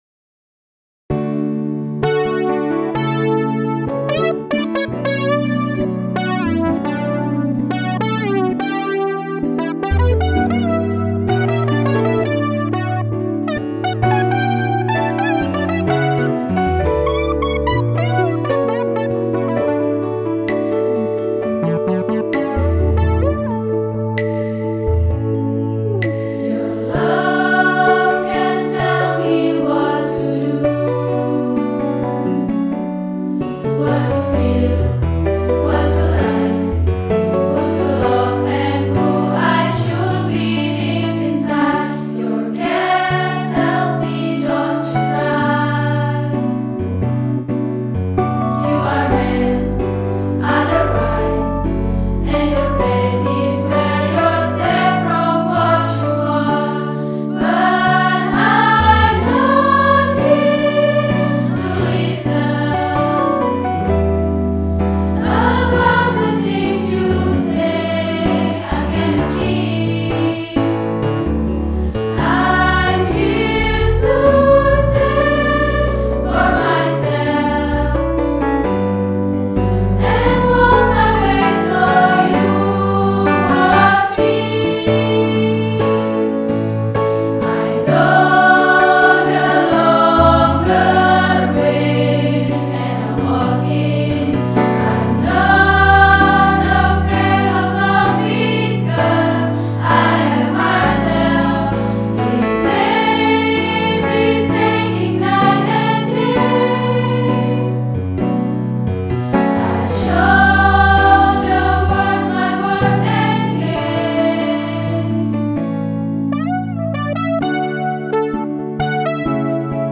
Womern Empowerment Song